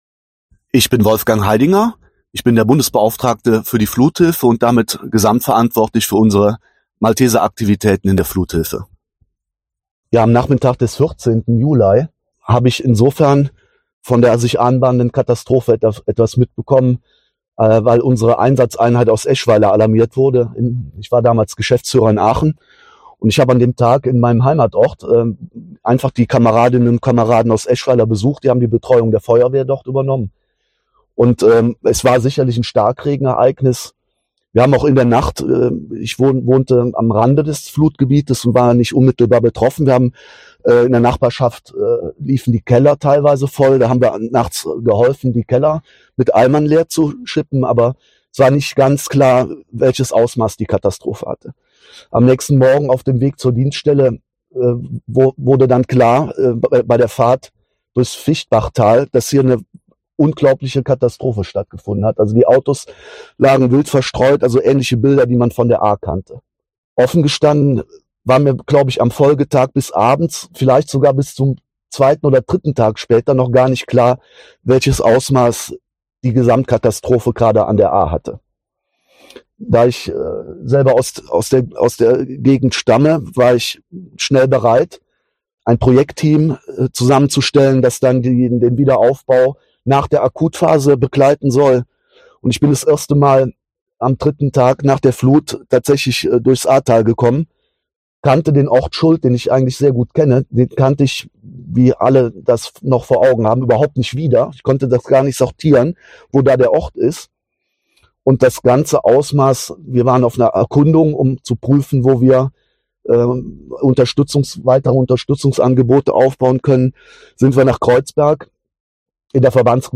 Sechs von ihnen teilen ihre persönlichen Eindrücke mit uns, sprechen ungefiltert und offen von ihren Erfahrungen, die sie bis heute begleiten.